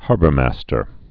(härbər-măstər)